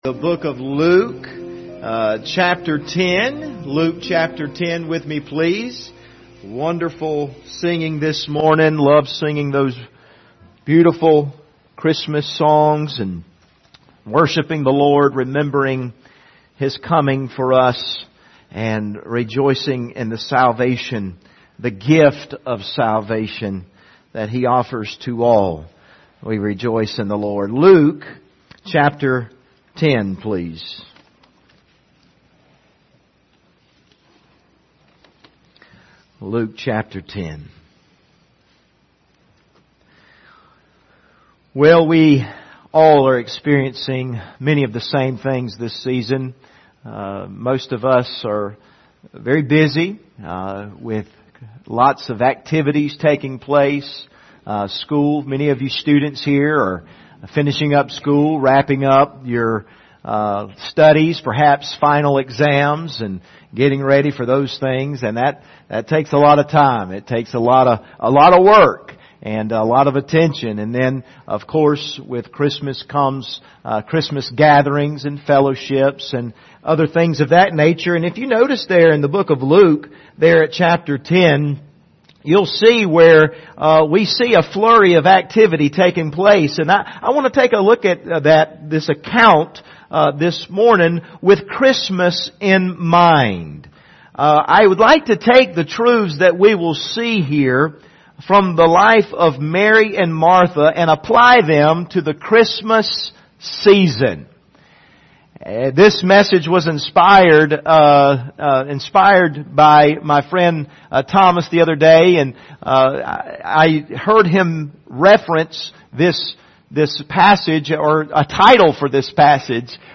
Passage: Luke 10:38-42 Service Type: Sunday Morning